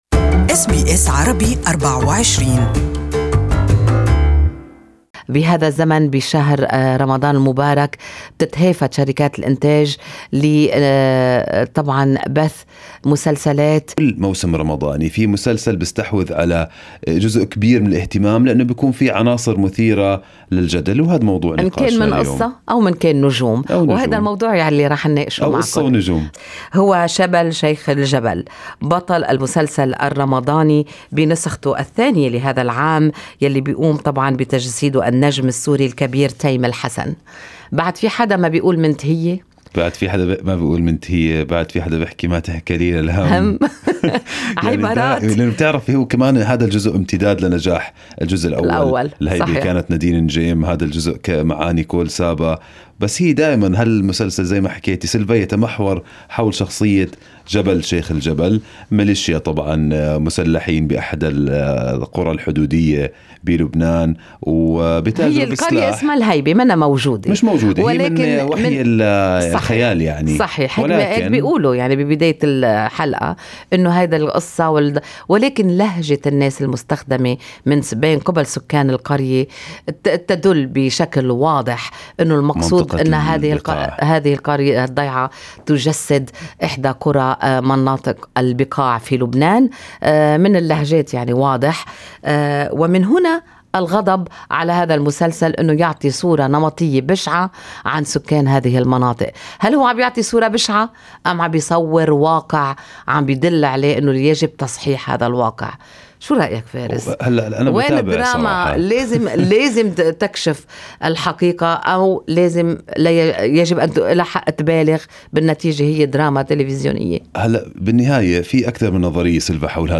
في مقابلة